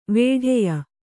♪ vēḍheya